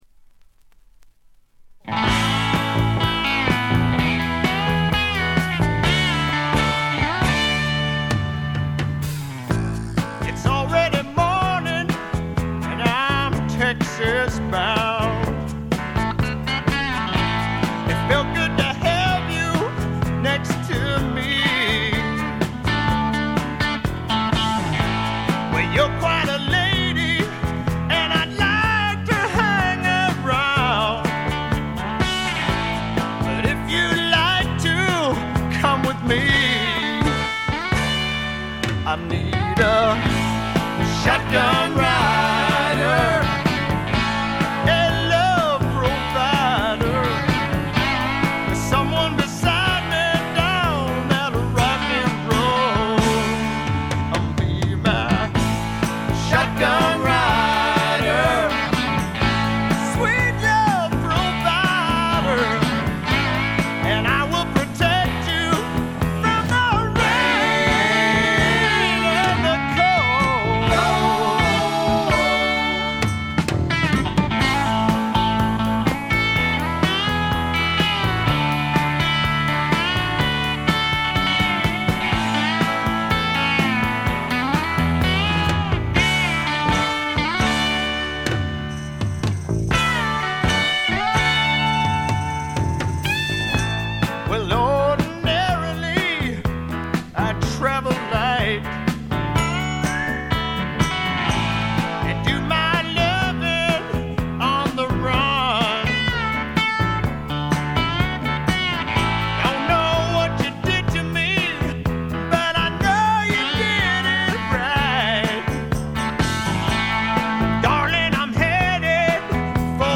ホーム > レコード：米国 スワンプ
部分試聴ですが、微細なノイズ感のみ。
試聴曲は現品からの取り込み音源です。